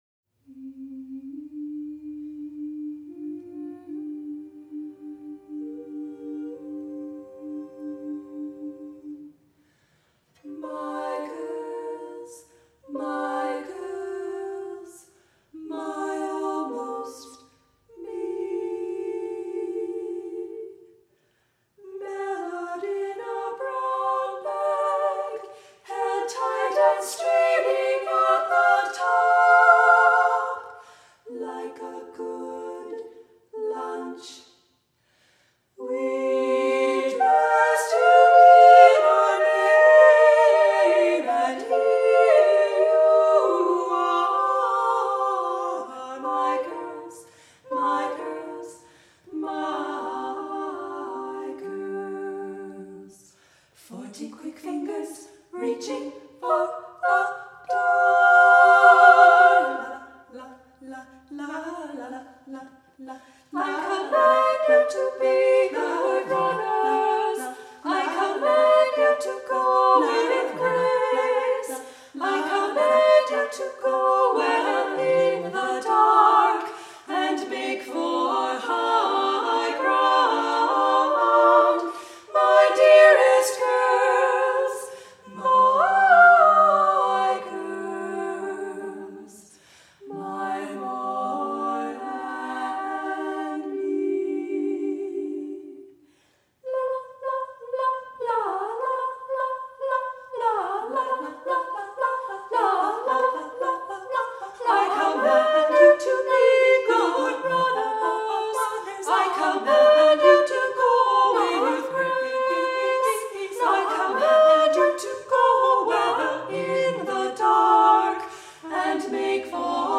for SSA Chorus (1998)